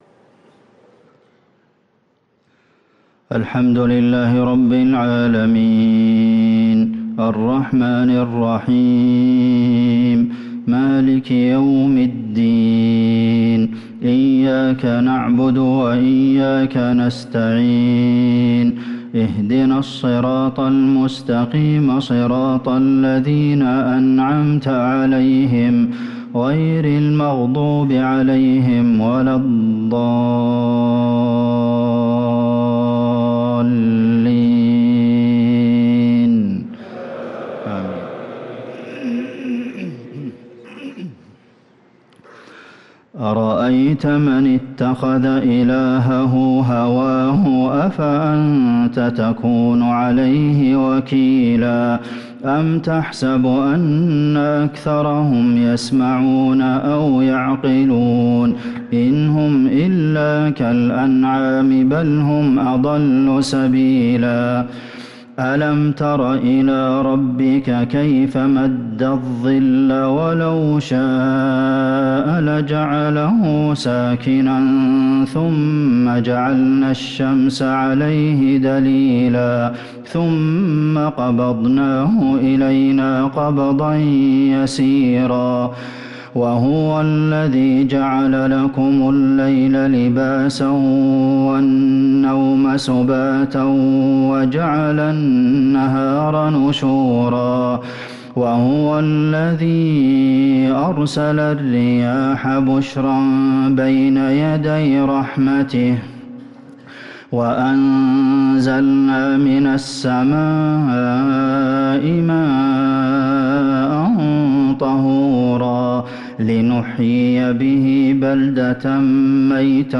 صلاة العشاء للقارئ عبدالمحسن القاسم 8 جمادي الأول 1445 هـ